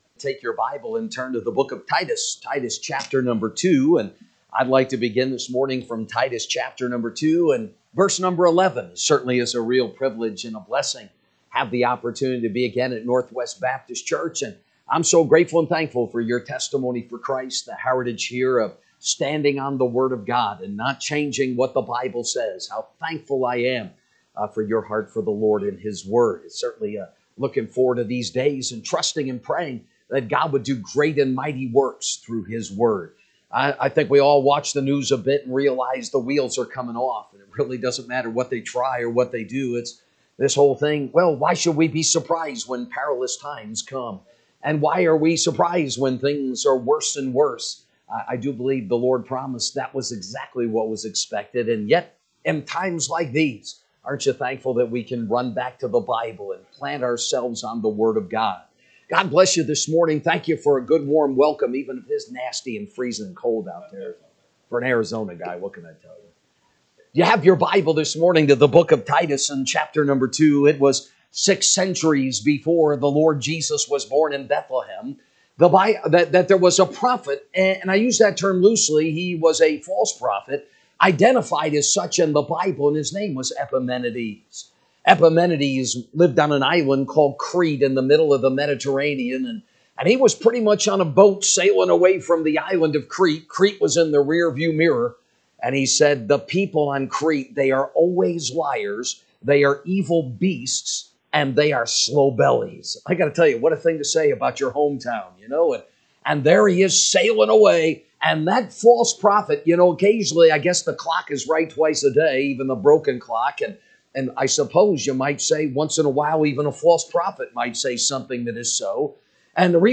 March 16, 2025 Adult Bible Study